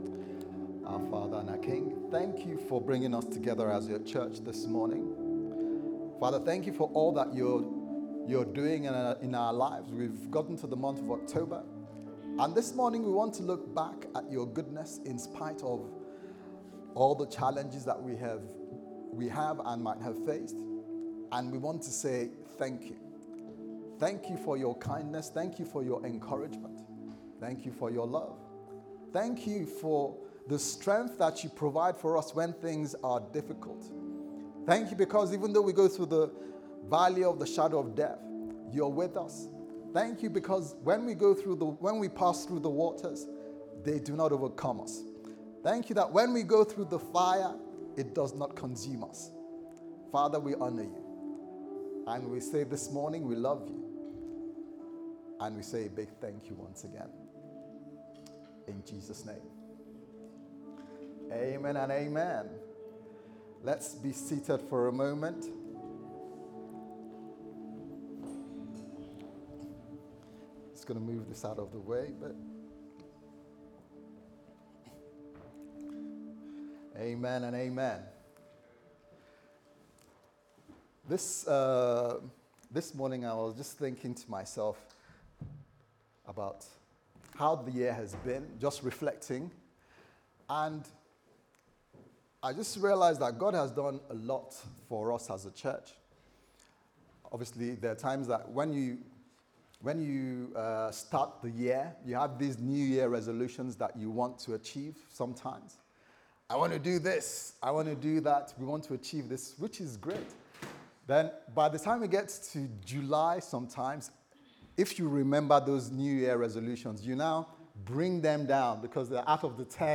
What Really Matters Service Type: Sunday Service Sermon « Destined For Greatness